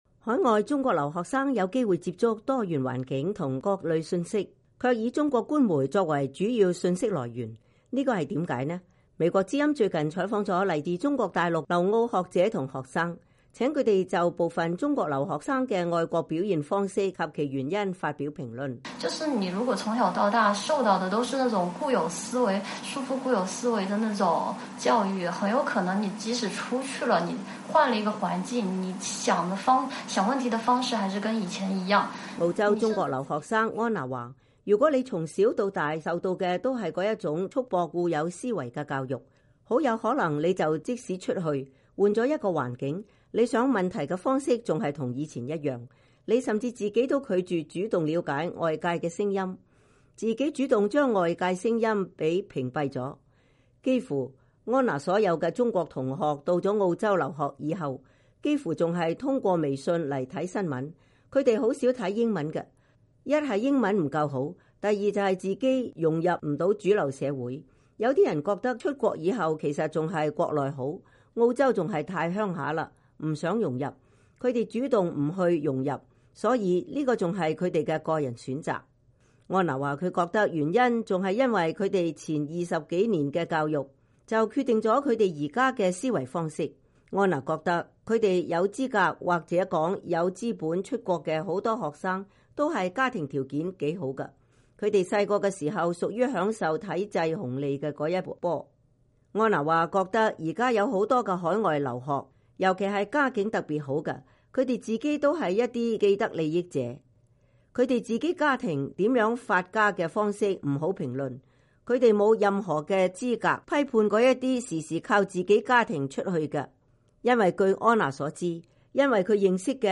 （根據採訪視頻、錄音整理，受訪者觀點不代表美國之音）